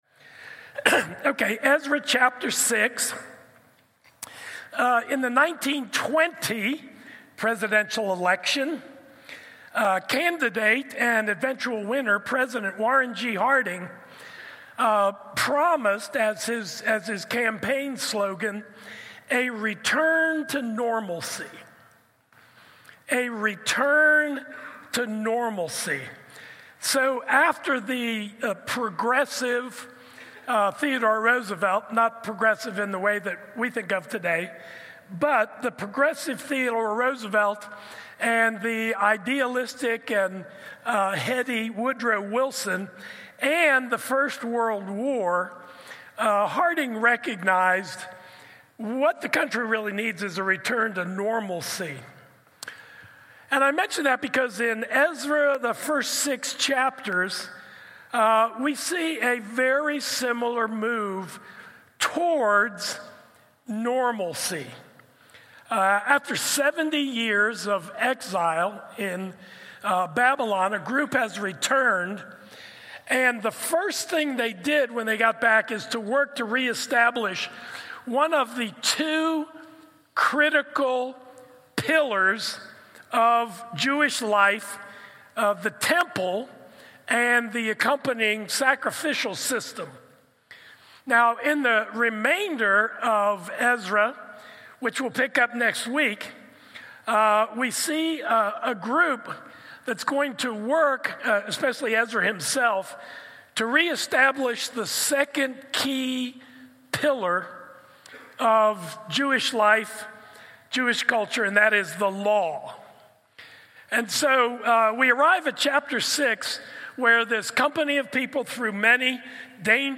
A message from the series "Judges: {Un} Faithful."